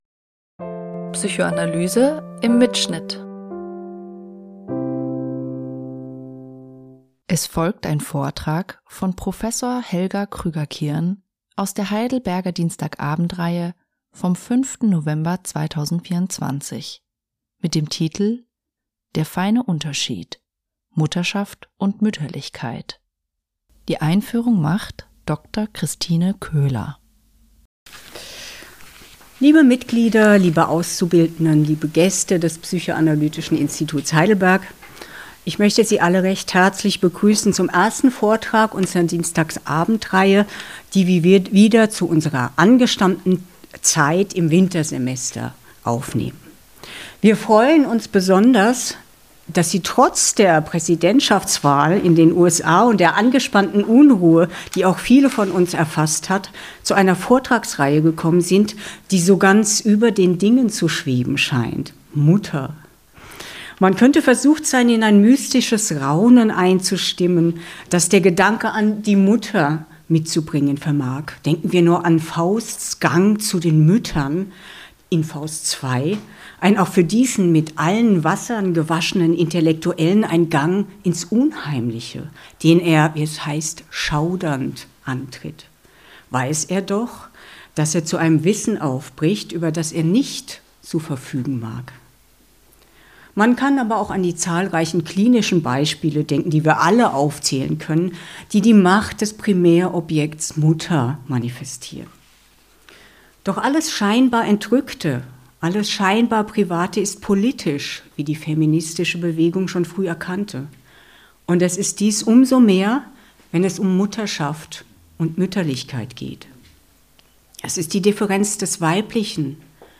Der Vortrag wurde am 5. November 2024 im Rahmen der Heidelberger Dienstagabendreihe gehalten.